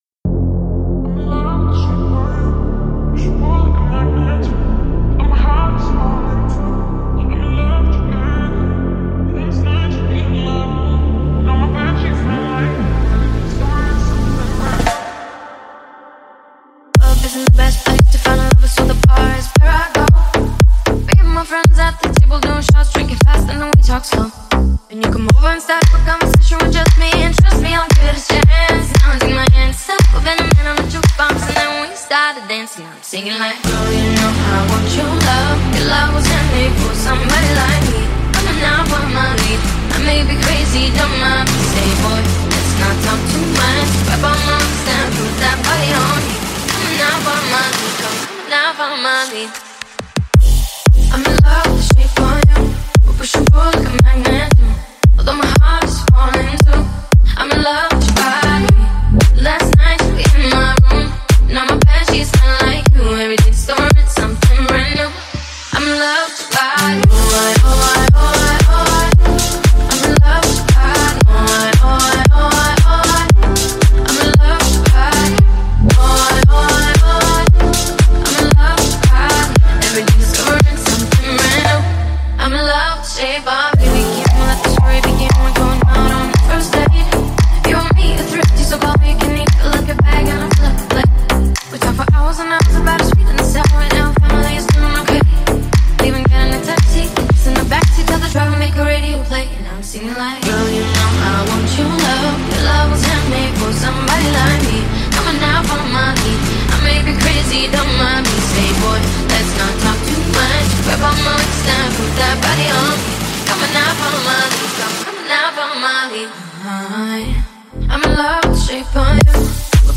это зажигательный трек в жанре поп и танцевальной музыки.